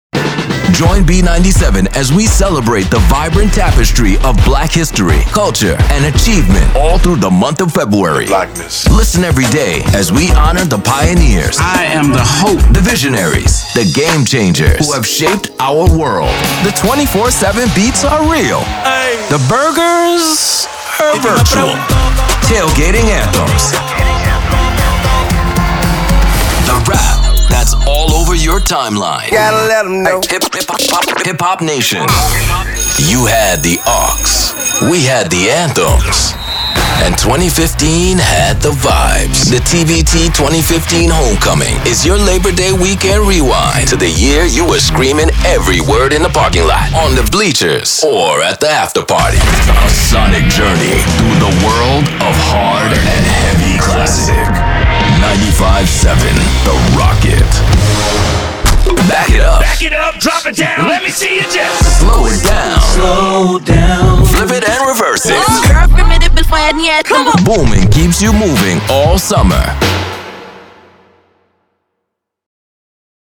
Profound. Resonant. Real | Voiceovers
Radio Imaging
Profound. Resonant. Real.